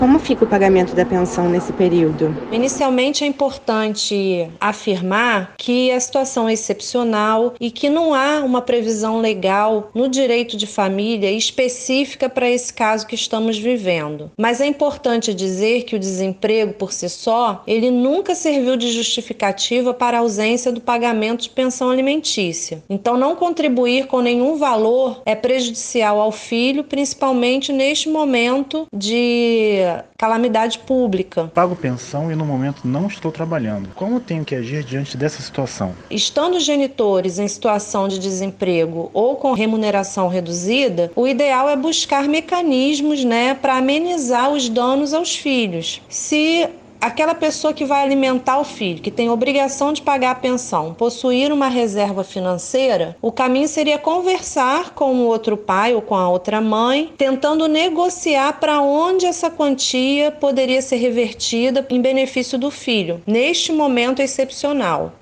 POVO-FALA-01-1.mp3